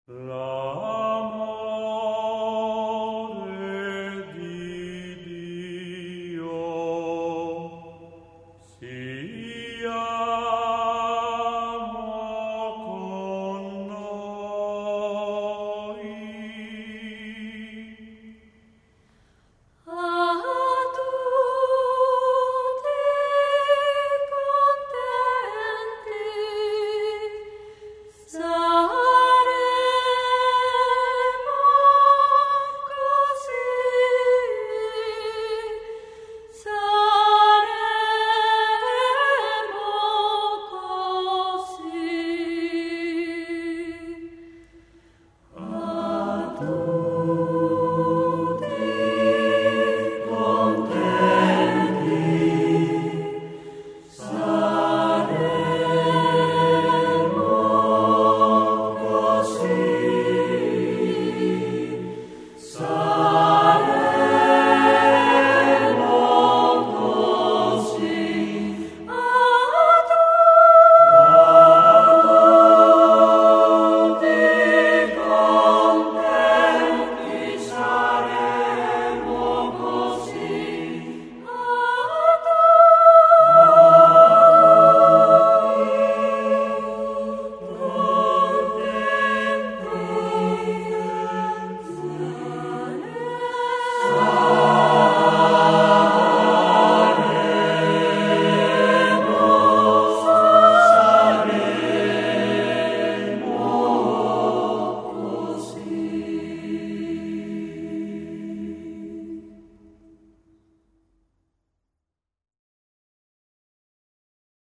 Dependiendo del estilo y del tema concreto, cantamos a capella o con instrumentación de acompañamiento opcional (teclado).